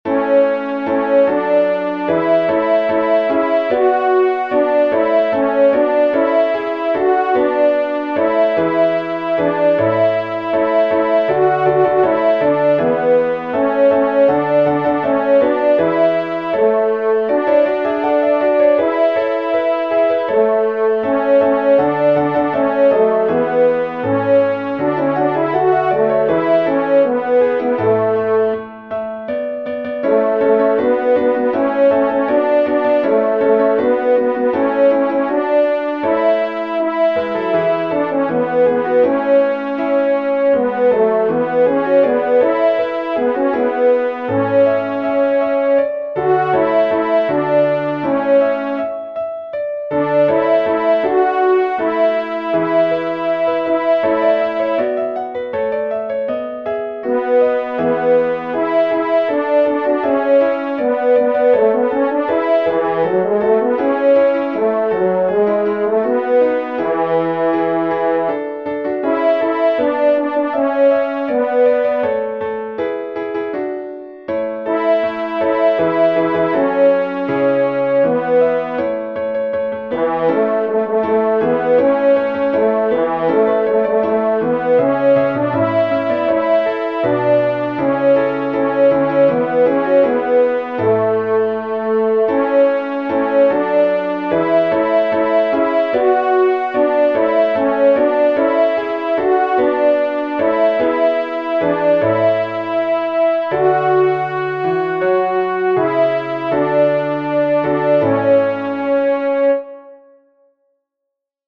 exultate_justi-cpdl-tenor.mp3